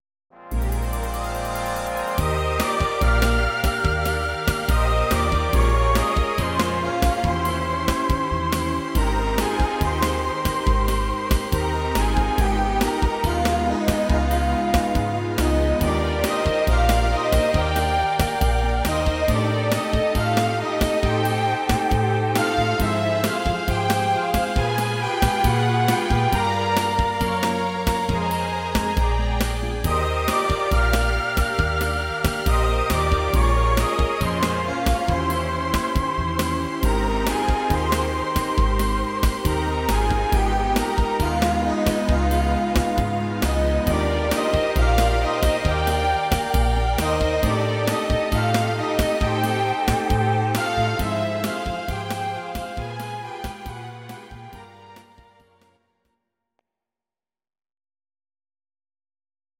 Audio Recordings based on Midi-files
Our Suggestions, Pop, Instrumental, 1970s